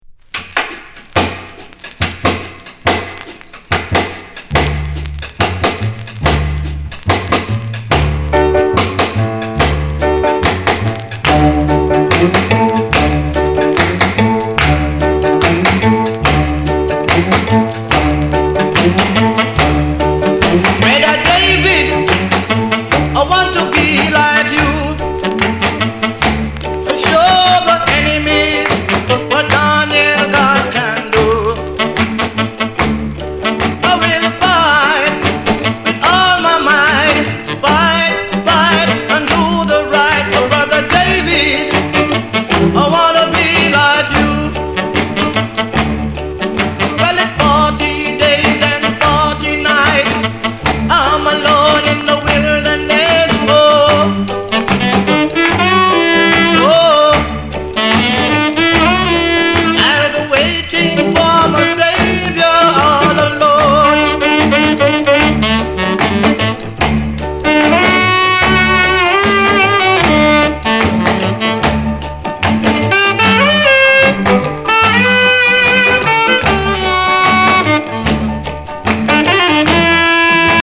Genre: Rhythm & Blues